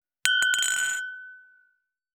299チキン,コチン,カチコチ,チリチリ,シャキン,カランコロン,パリーン,ポリン,トリン,
コップ効果音厨房/台所/レストラン/kitchen食器
コップ